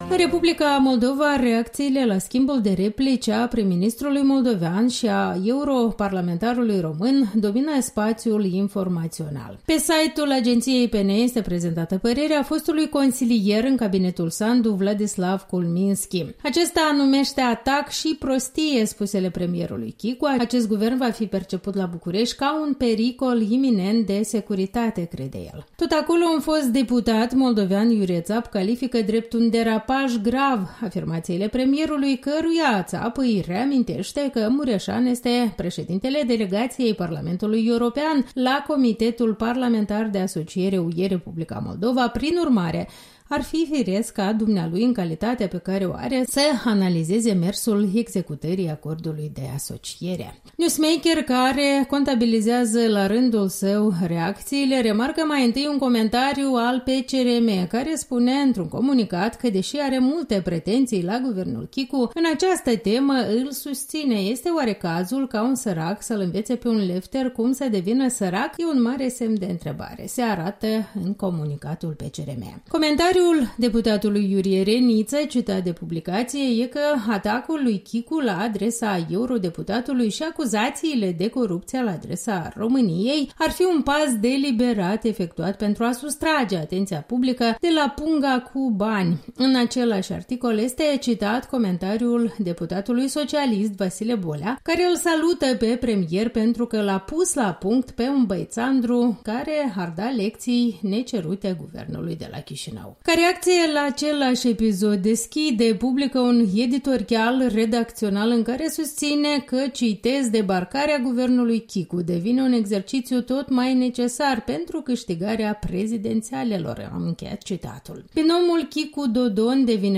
Revista presei matinale.